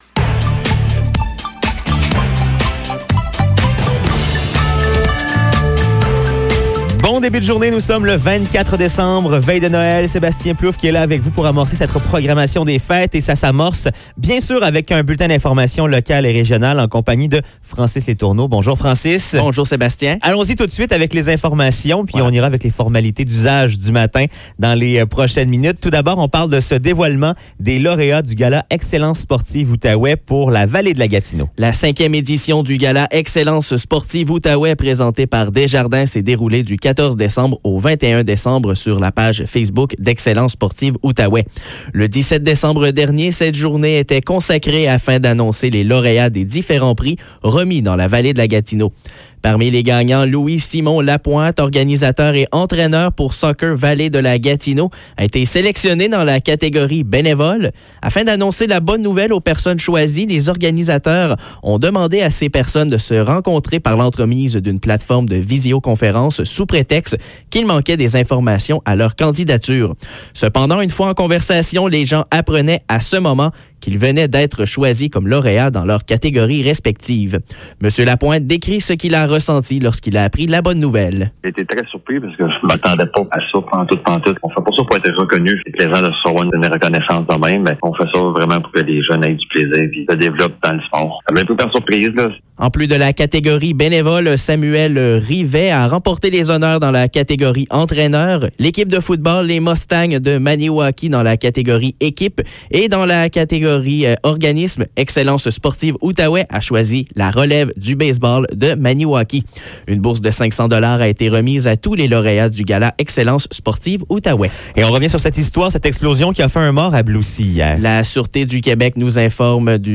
Nouvelles locales - 24 décembre 2021 - 7 h